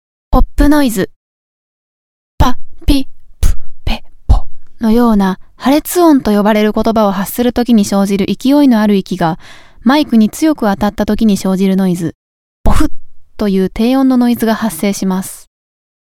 あえてノイズが出るように喋ってもらいました）
いろんなノイズ③　ポップノイズ
マイクに当たったときに出るノイズ。「ボフッ」という低音のノイズが発生します。
03_Pop-Noise.mp3